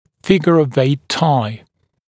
[‘fɪgə əv eɪt taɪ][‘фигэ ов эйт тай]связка «восьмёркой»